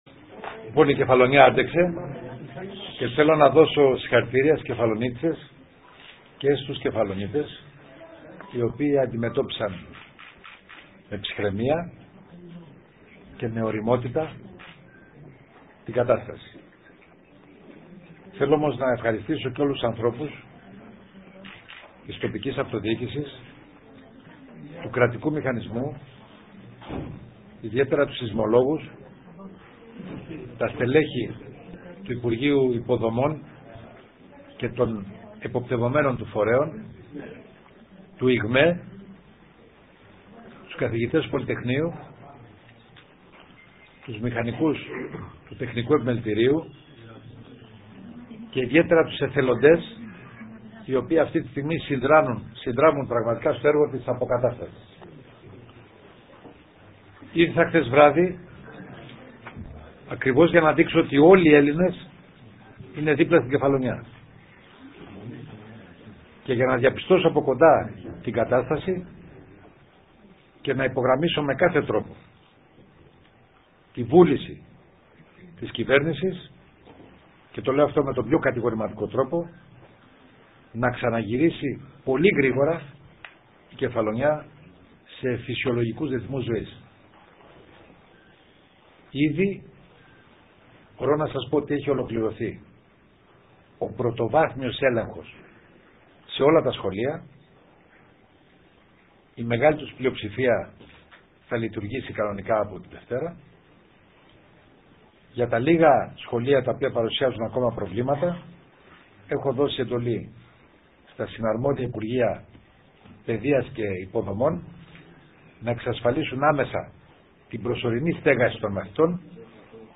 Για λίγο  πήγε στο ξενοδοχείο και στην συνέχεια στο Δημαρχείο παραχώρησε συνέντευξη τύπου.
ΣΥΝΕΝΤΕΥΞΗ Α. ΣΑΜΑΡΑ